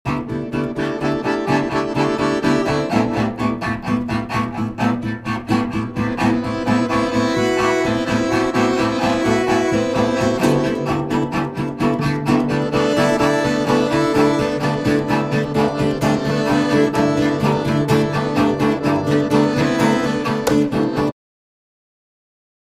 acc. git
accordion
cello